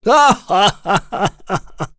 Dakaris-Vox_Happy3.wav